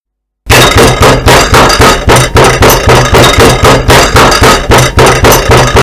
Звуки удара по столу
Громкий